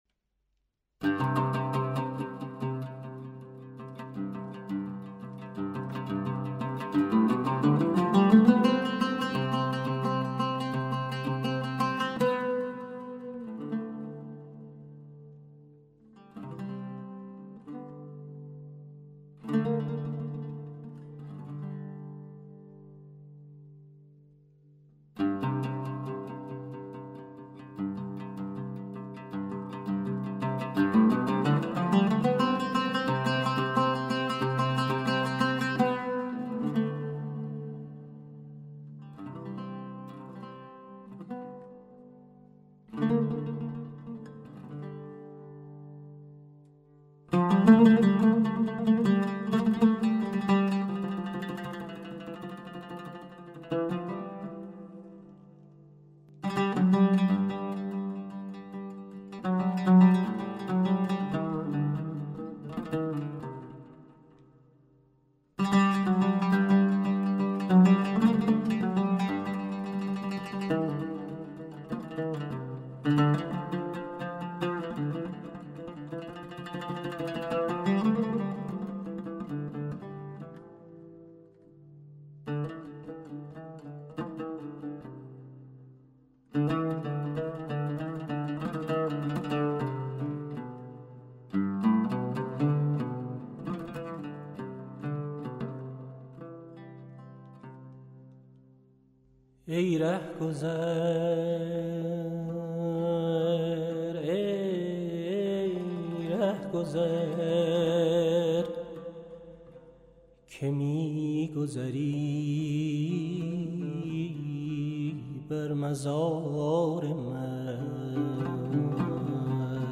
ساز‌وآوازی بداهه
به عنوان خواننده
با نوازندگی سازِ عود، ساز و آوازی بداهه